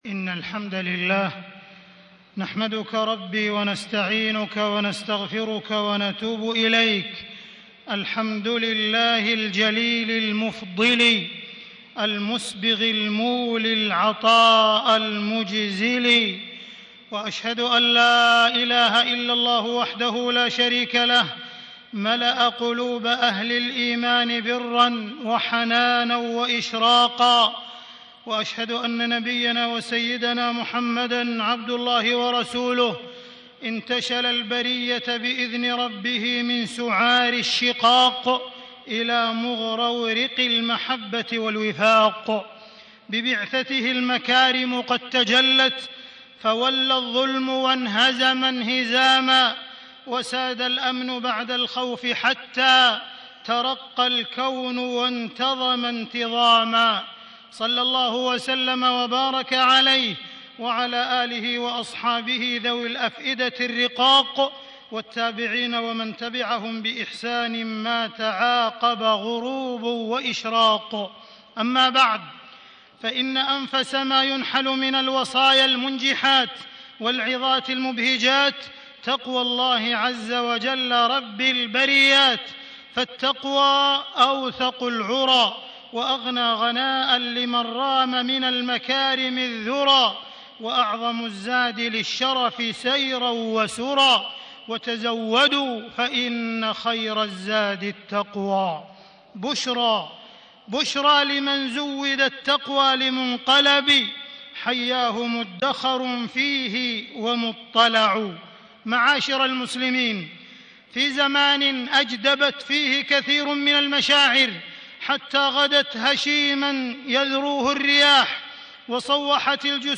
تاريخ النشر ٦ صفر ١٤٣٦ هـ المكان: المسجد الحرام الشيخ: معالي الشيخ أ.د. عبدالرحمن بن عبدالعزيز السديس معالي الشيخ أ.د. عبدالرحمن بن عبدالعزيز السديس العنف الأسري وآثاره على المجتمع